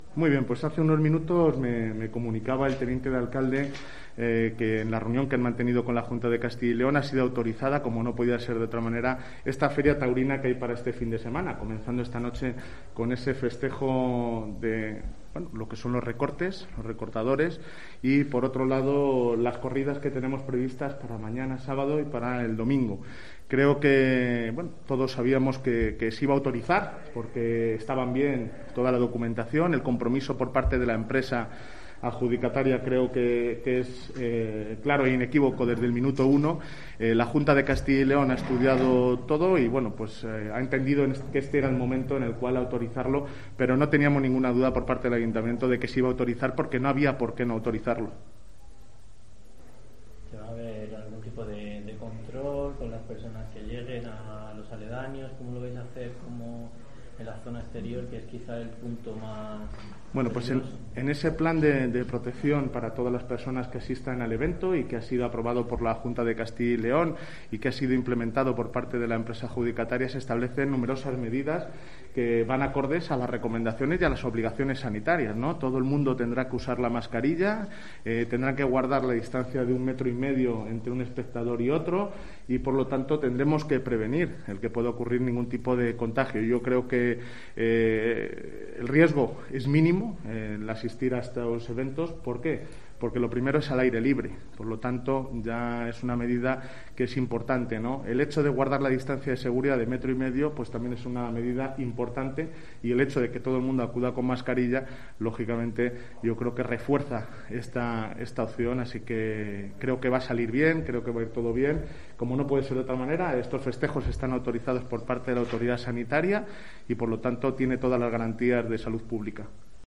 El delegado territorial, José Francisco Hernandez Herrero, ha explicado en COPE Ávila que “el principal escollo era el aforo”, finalmente será en forma de “trebolina” es decir usando un asiento si y tres no. (Escuchar audio).
El alcalde de Ávila, co-organizador del evento, ha asegurado que “hay riesgo mínimo por ir a la plaza”.